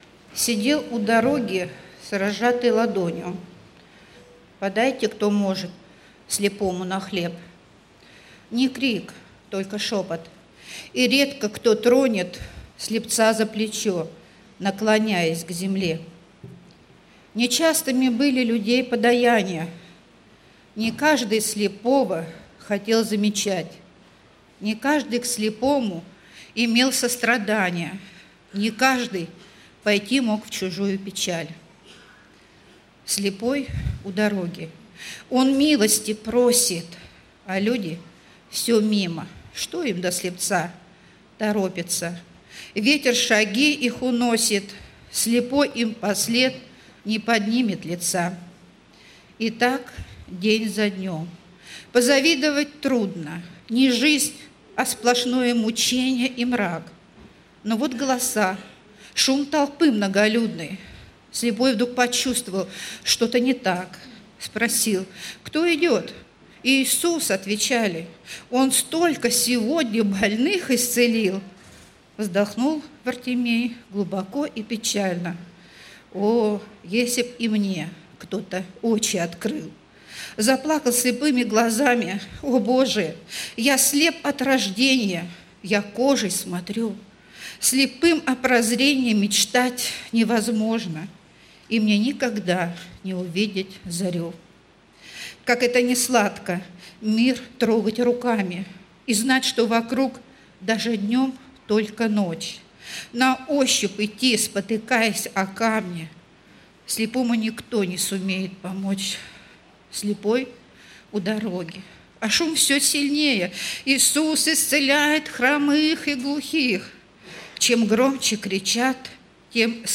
Богослужение 25.08.2019
Стихотворение